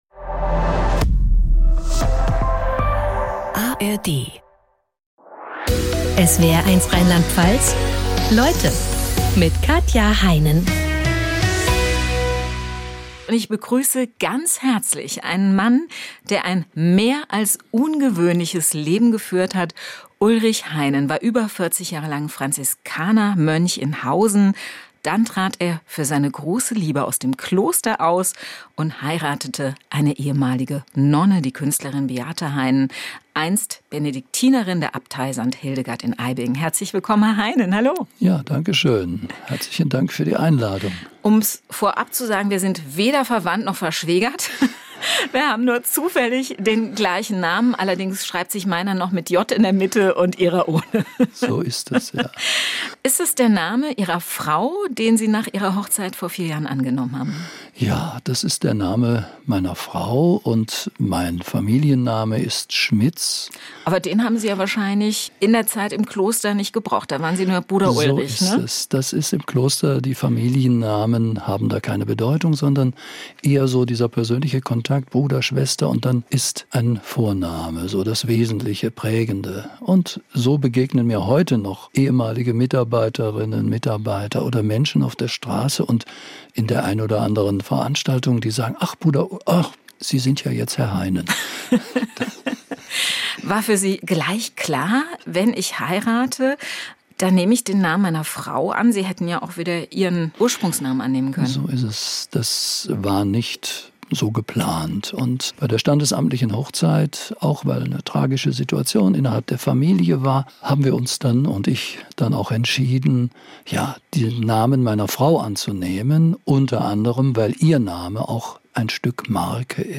Jeden Tag talken unsere SWR1 Leute-Moderator:innen in Baden-Württemberg und Rheinland-Pfalz mit interessanten und spannenden Gästen im Studio.